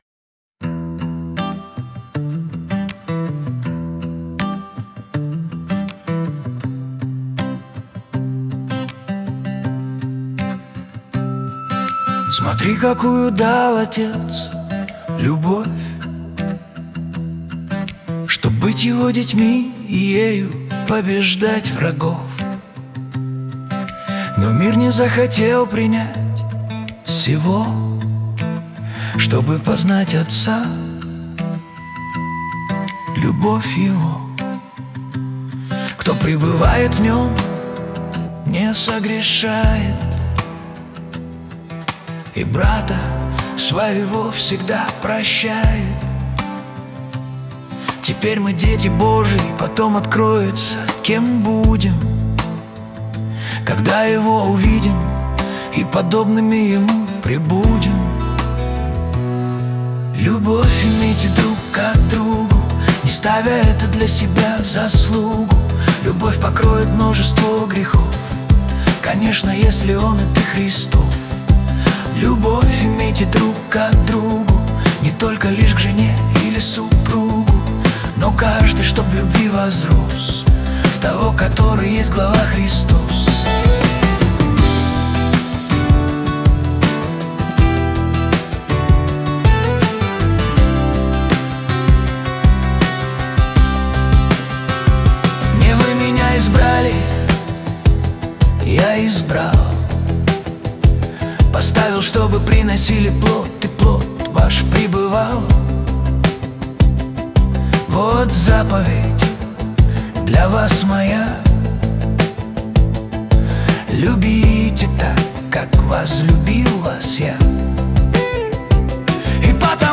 песня ai
183 просмотра 909 прослушиваний 56 скачиваний BPM: 81